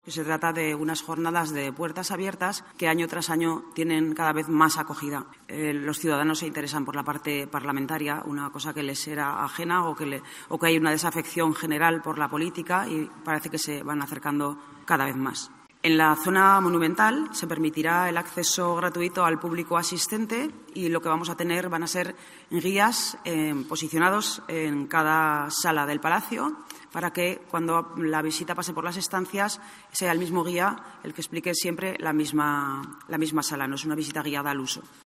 La presidenta de las Cortes, Marta Fernández, explica el programa de visitas guiadas a la Aljafería